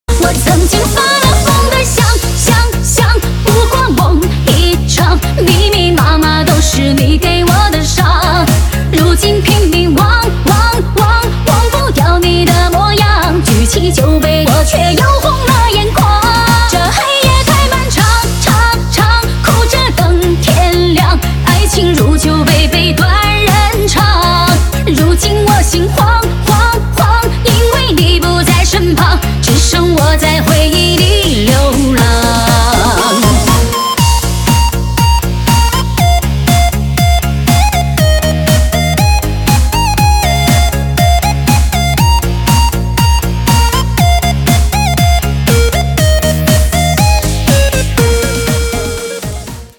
DJ手机铃声